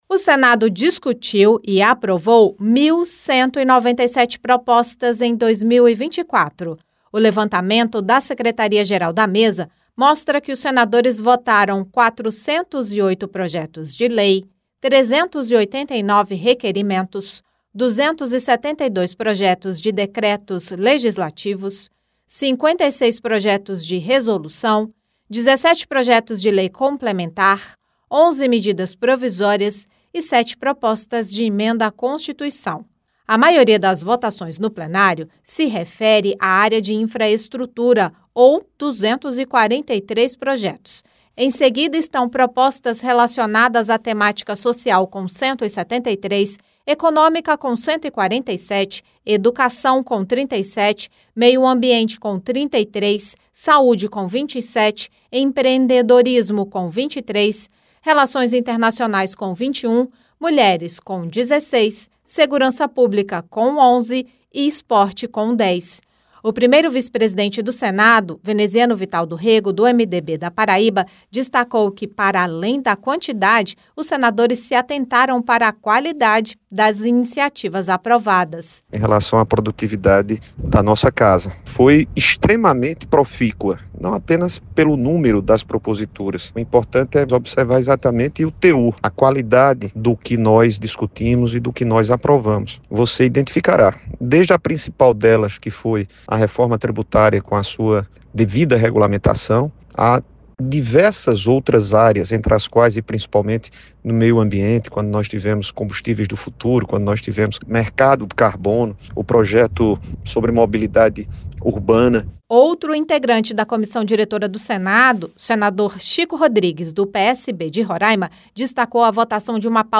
Ao comentar a votação de 1.197 propostas em 2024, o primeiro-vice-presidente do Senado, Veneziano Vital do Rêgo (MDB-PB), declarou que não apenas a quantidade impessiona, mas sobretudo a qualidade do que foi aprovado pelos senadores. Ele destacou a regulamentação da reforma tributária, a criação do mercado de carbono e a nova política de mobilidade urbana. Já o terceiro-secretário, senador Chico Rodrigues (PSB-RR), citou a aprovação do pacote de corte de gastos do governo, a reforma do Ensino Médio e a regulamentação da Inteligência Artificial.